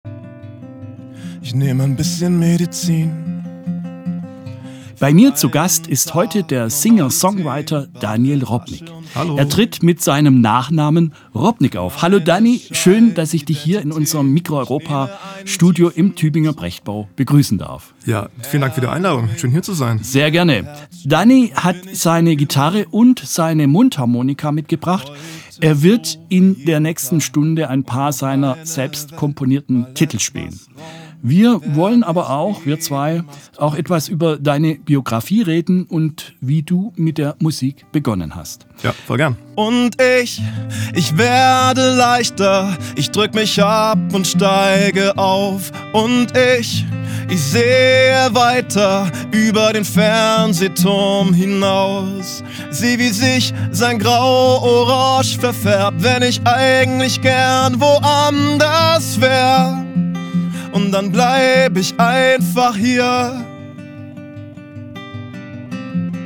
mit seiner Gitarre
alles live im Studio.